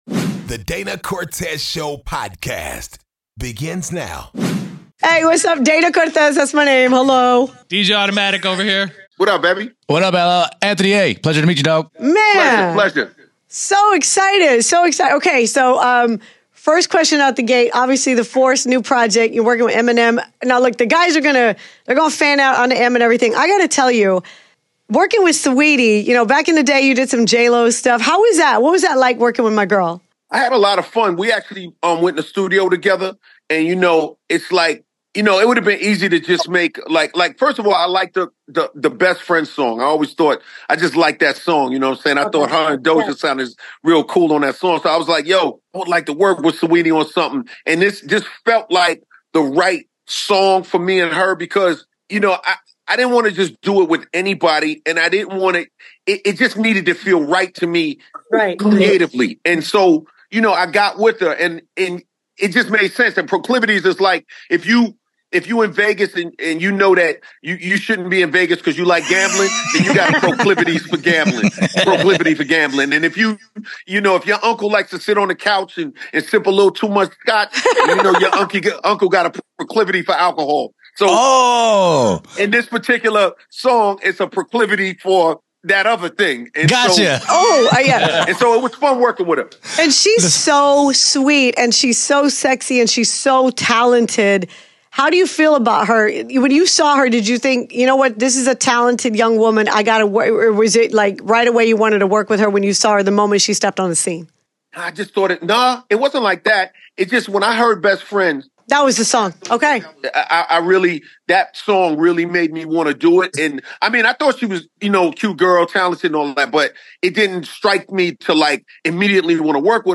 DCS Interviews Hip Hop Legend LL Cool J